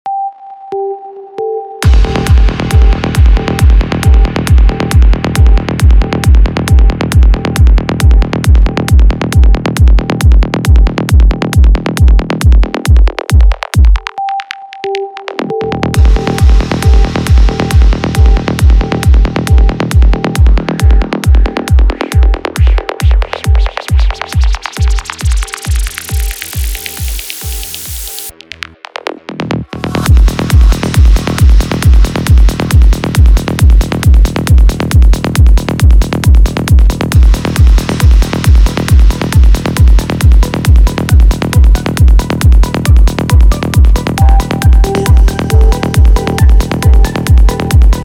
• Качество: 320, Stereo
Electronic
без слов
Trance
быстрые
psy-trance
Стиль: trance